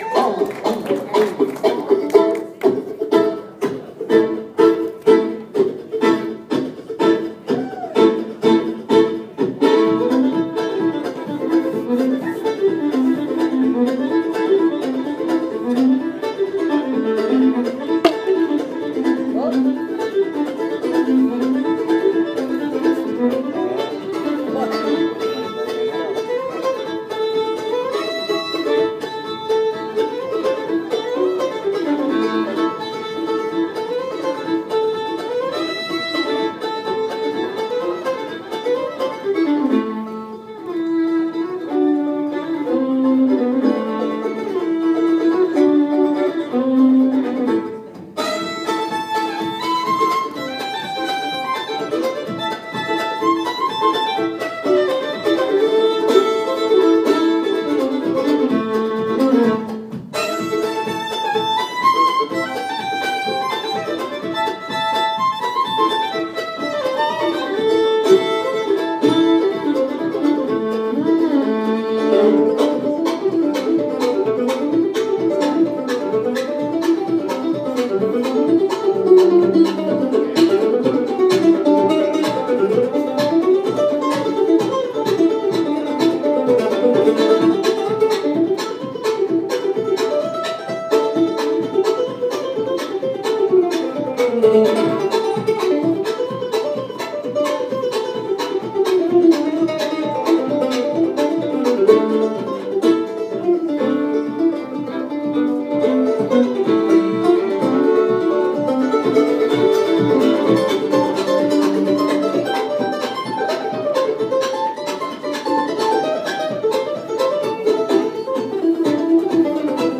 The repertoire is straight ahead bluegrass and country, in the tradition.
I did manage to turn on my iPhone sound recorder for Jerusalem Ridge and it came out enough to enjoy.
mandolin and fiddle